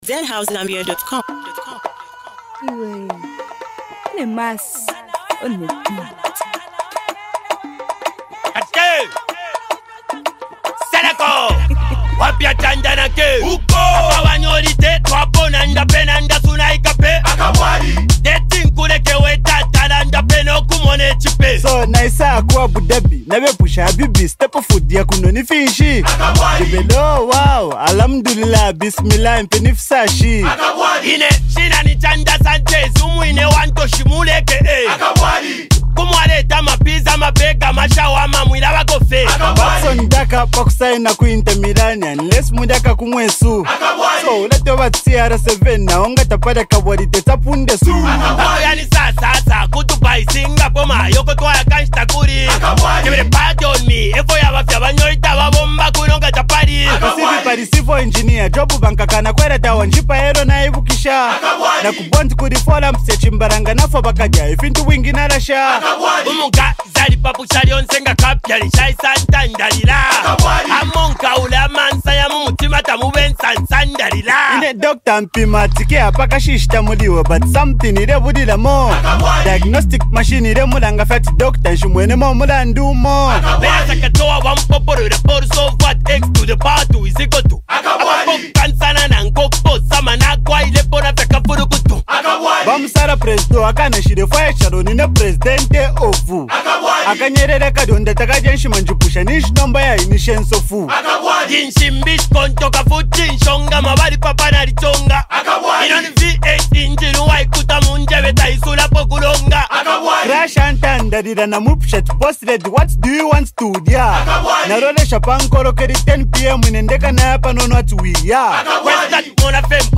The dynamic duo is back!
vibey anthem
With their signature energy and catchy hooks
StreetAnthem